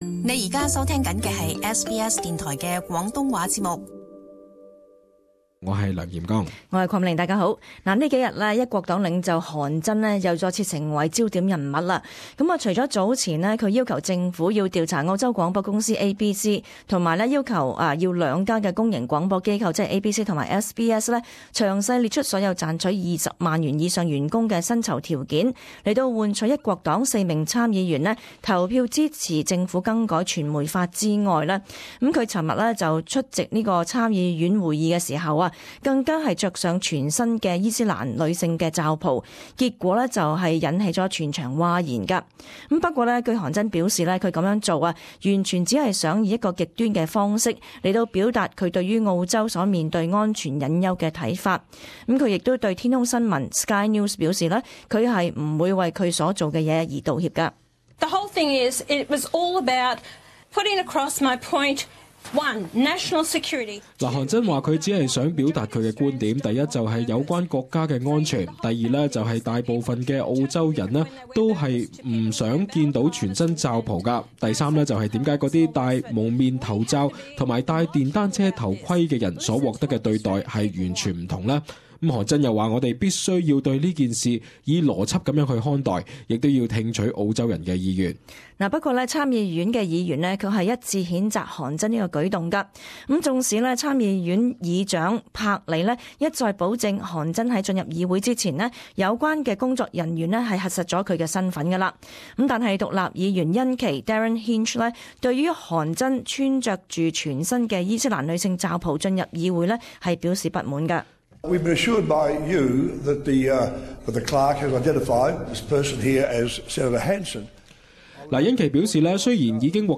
【時事報導】韓珍穿罩袍入參議院惹爭議